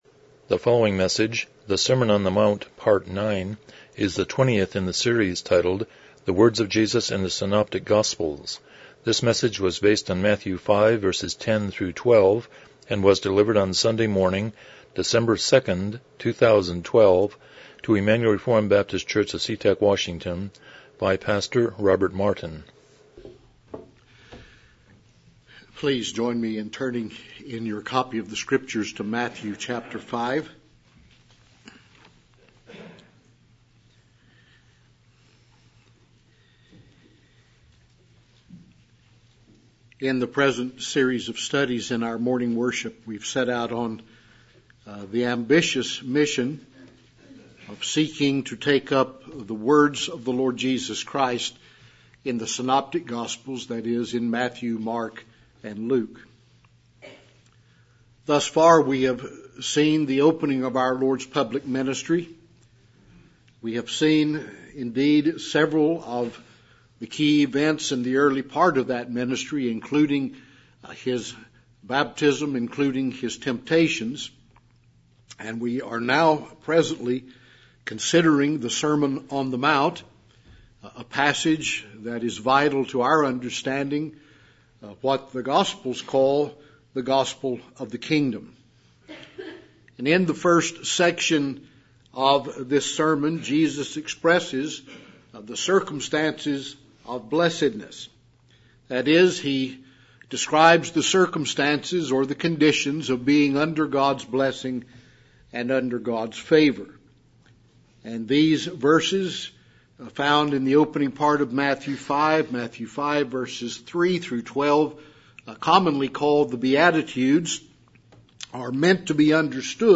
Passage: Matthew 5:10-12 Service Type: Morning Worship